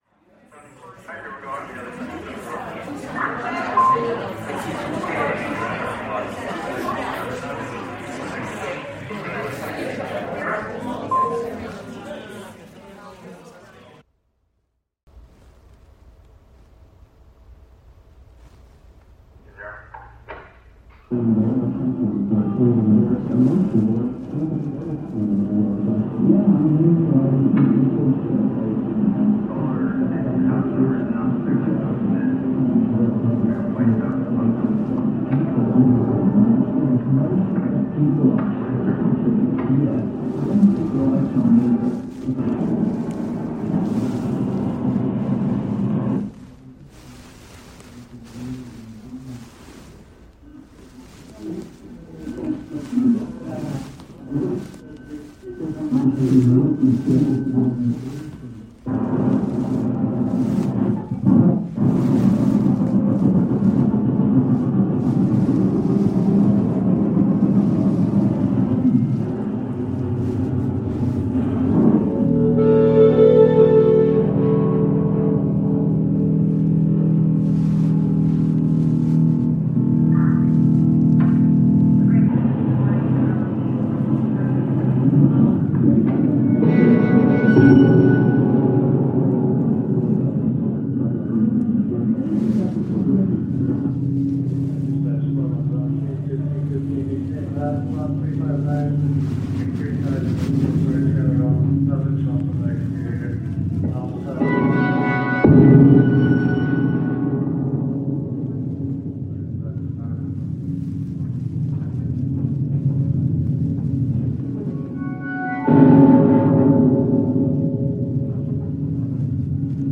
performed via an electronic interface woven into hair